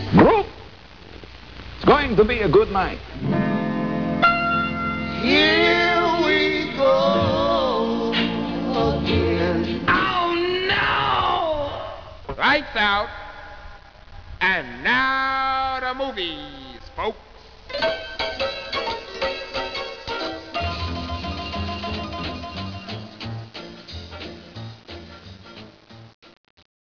This is how Ghoulardi started his show circa '66